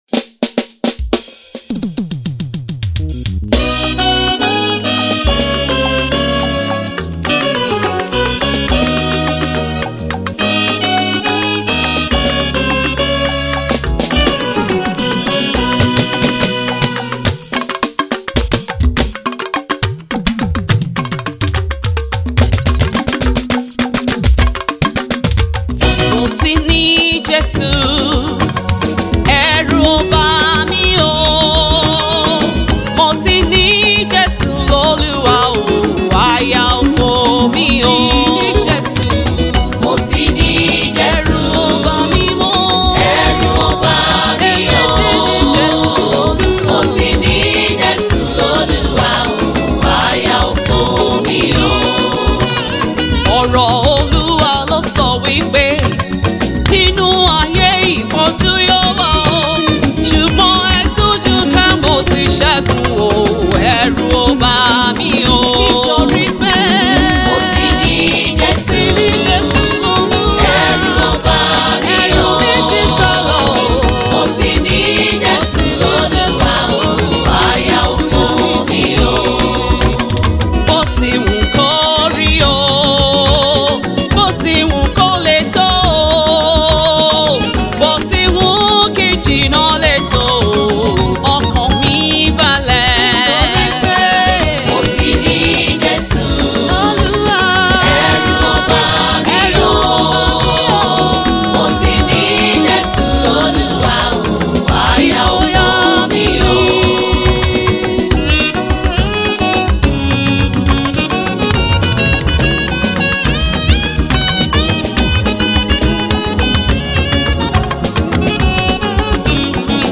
the song comes with a great delivery with African blend.